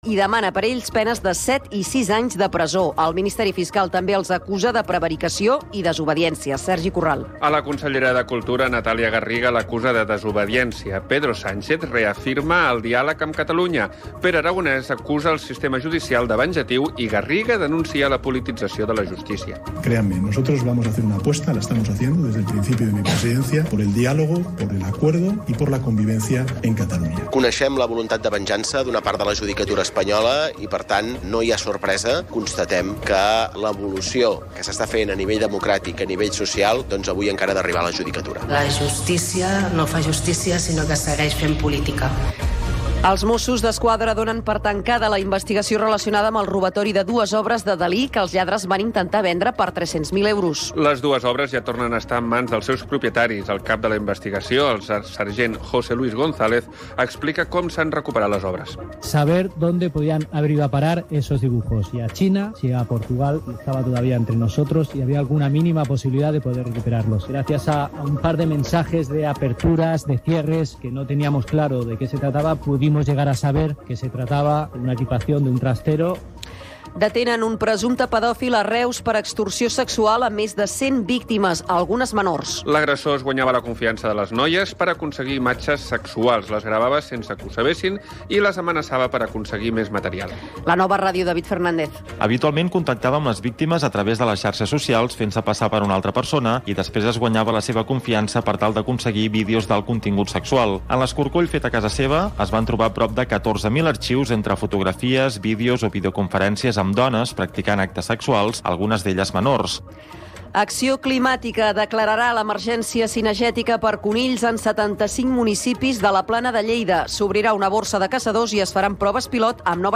Informatius Notícies en xarxa (edició vespre)